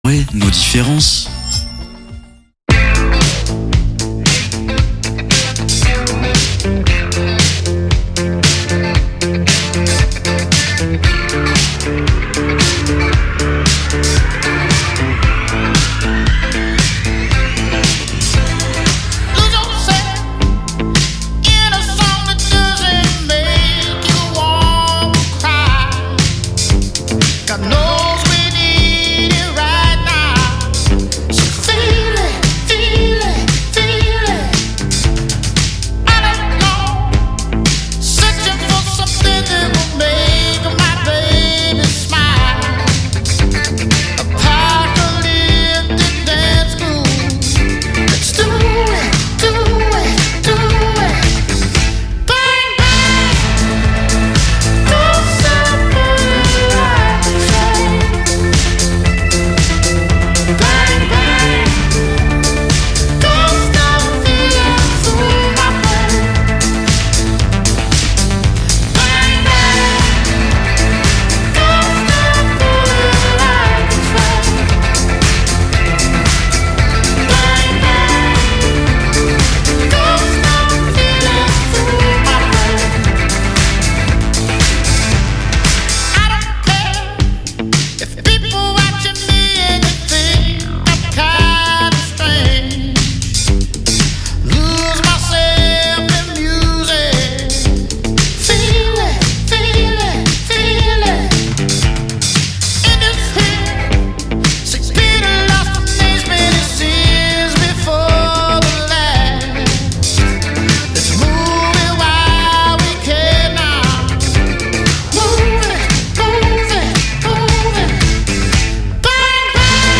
On enregistre en public aux Petites Folies ce nouvel épisode avec le concept de Comédie Musicale.